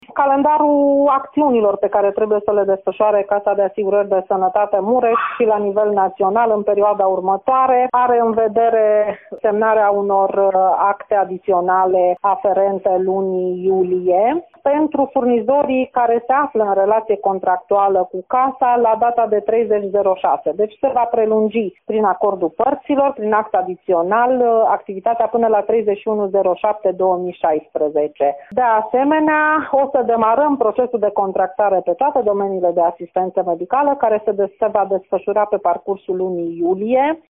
Președintele Casei Județene de Asigurări de Sănătate Mureș, Rodica Biro, a precizat că medicii sunt așteptați să semneze actele adiționale până în 31 iulie.